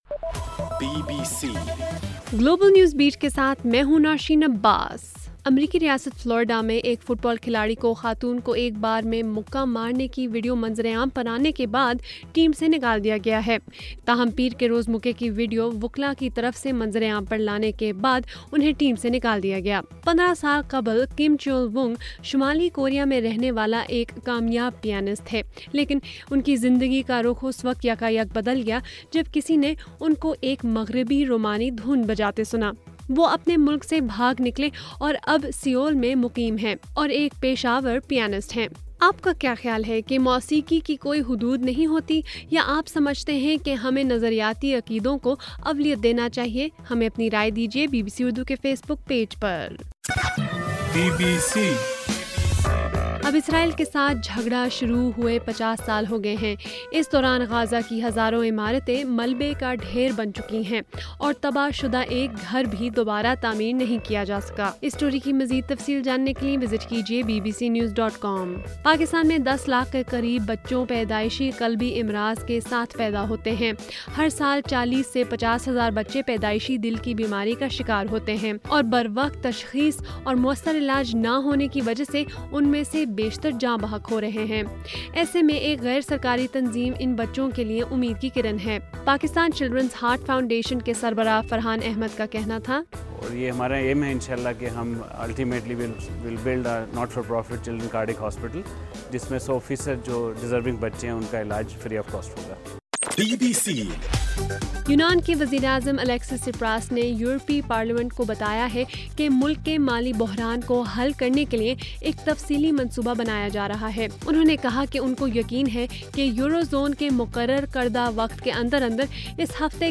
جولائی 8: رات 9 بجے کا گلوبل نیوز بیٹ بُلیٹن